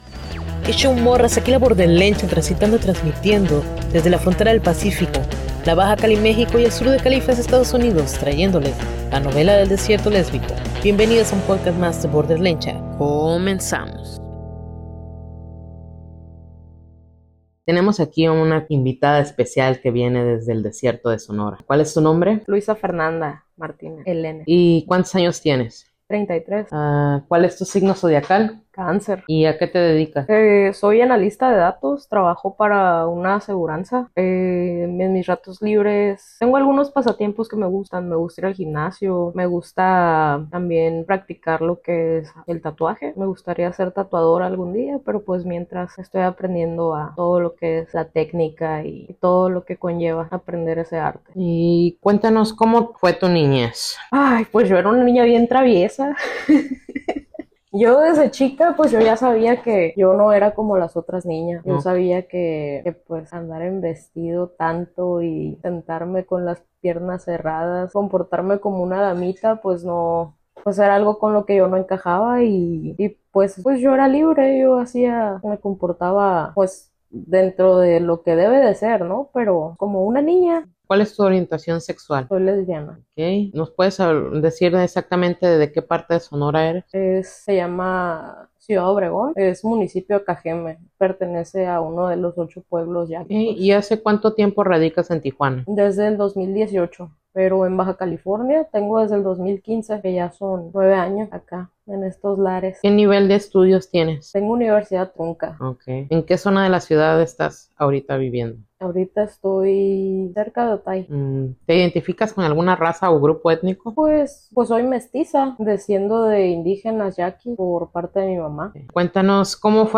Entrevista a Lesbiana Sonorense que reside en Tijuana.